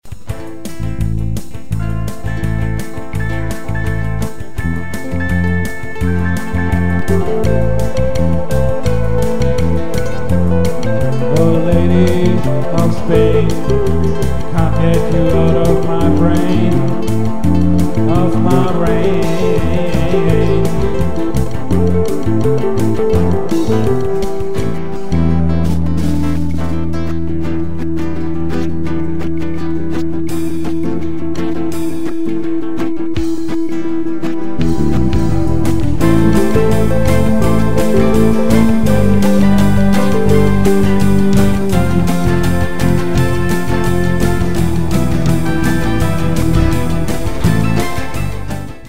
Download Eins meiner wenigen Rock'n'Roll Songs.